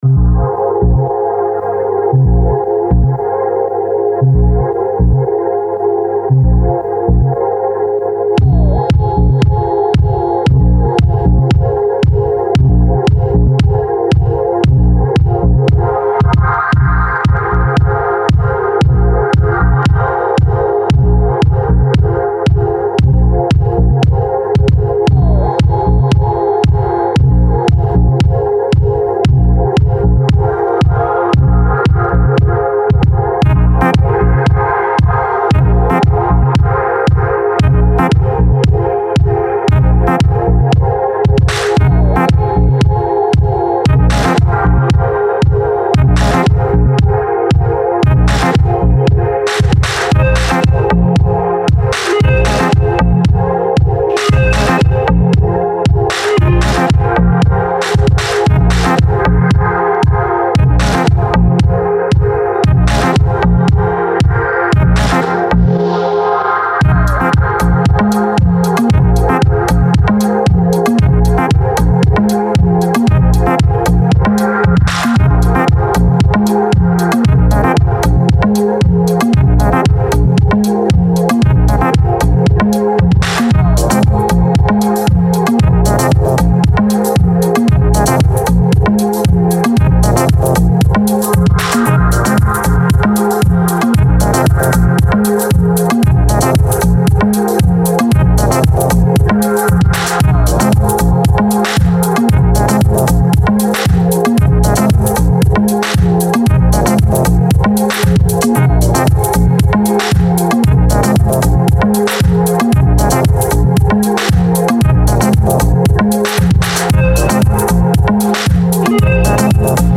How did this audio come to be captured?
No votes :raised_hands: Ableton, Machine and slammed through the 404SX compressor (sorry):